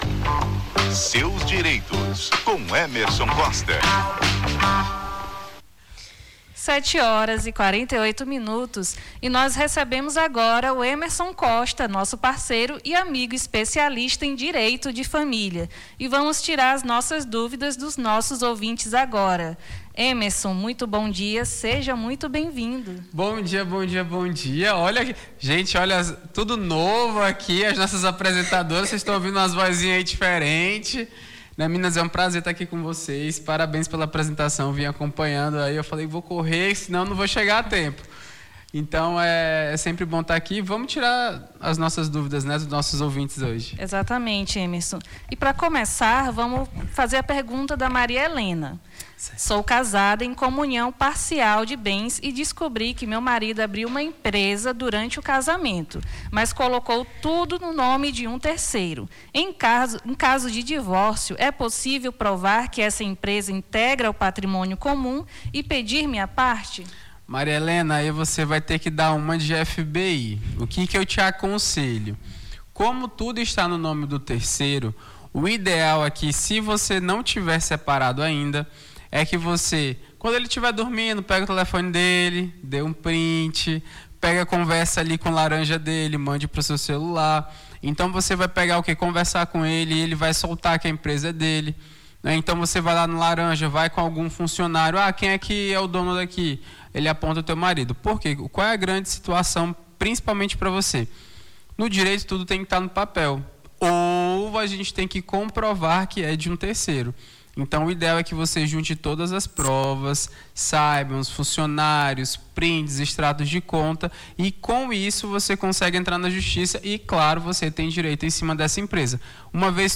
Na manhã desta sexta-feira, 16, conversamos com o advogado